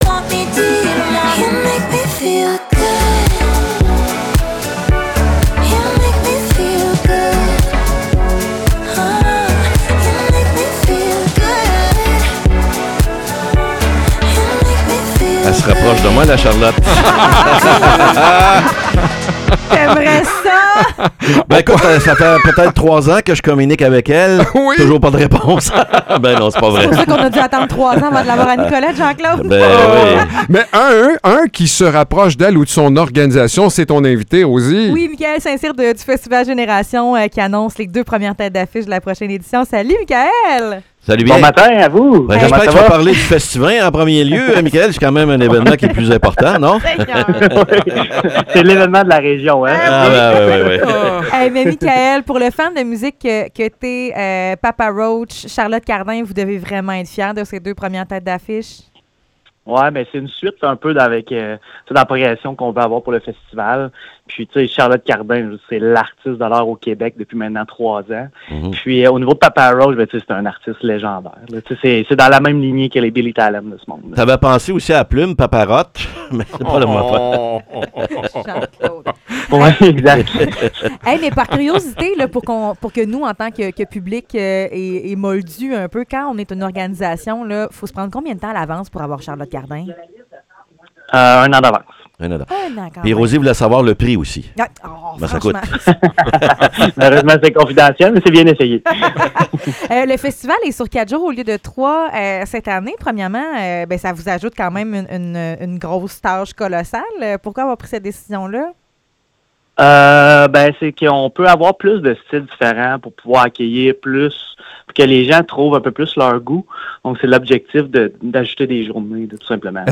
Entrevue avec le Festival Générations!